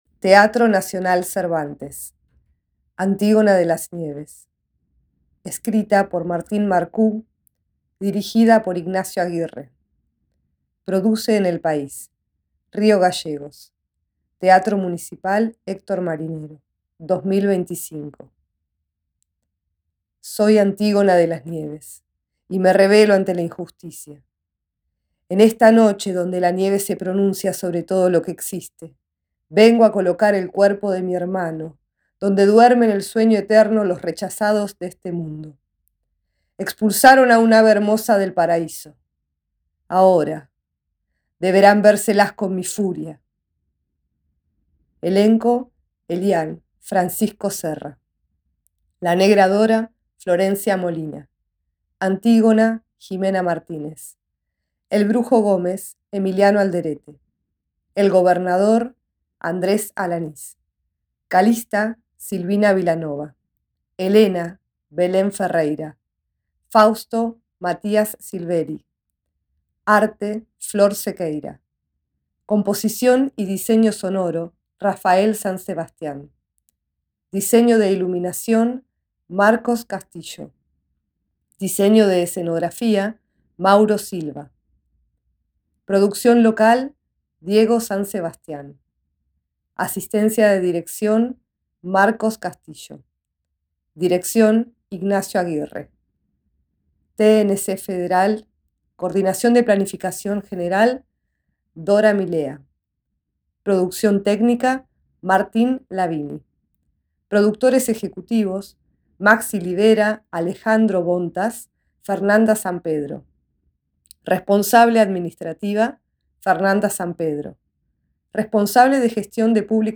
El siguiente audio corresponde a lectura del programa de mano del espectáculo Antígona de las Nieves